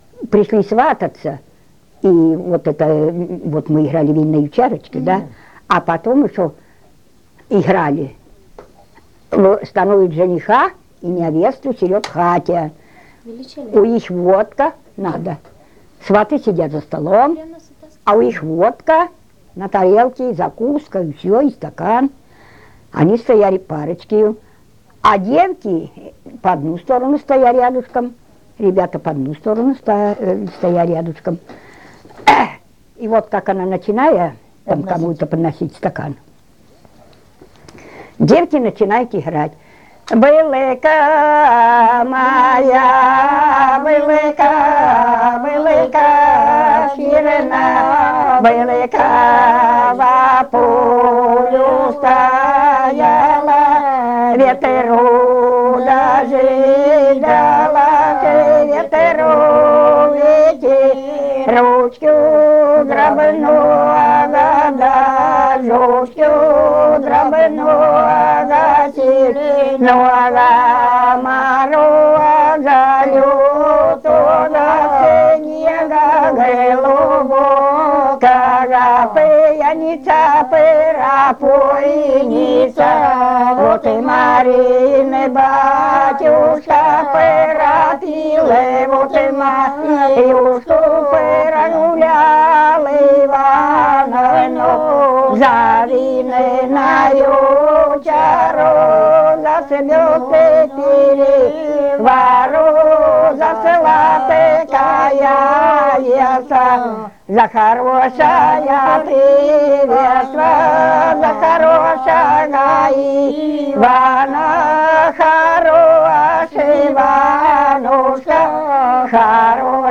01 Свадебная песня «Былка-чернобылка» в исполнении жительниц с. Васильдол Новооскольского р-на Белгородской обл. 01 Свадебная песня «Былка-чернобылка» в исполнении жительниц с. Васильдол Новооскольского р-на Белгородской обл. Место фиксации: Белгородская область, Новооскольский район, село Васильдол Год